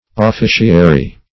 Search Result for " officiary" : The Collaborative International Dictionary of English v.0.48: Officiary \Of*fi"ci*a*ry\, a. Of or pertaining to an office or an officer; official.